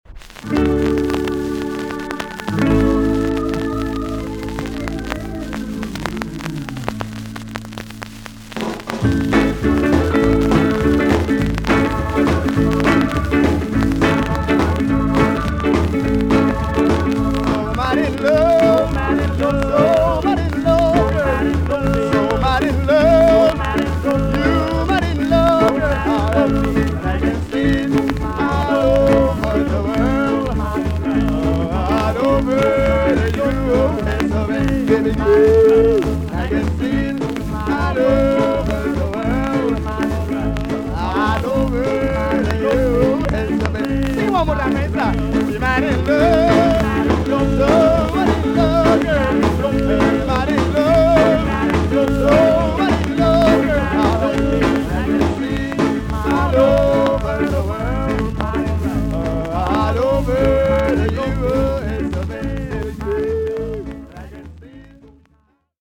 TOP >SKA & ROCKSTEADY
VG ok 全体的にチリノイズが入ります。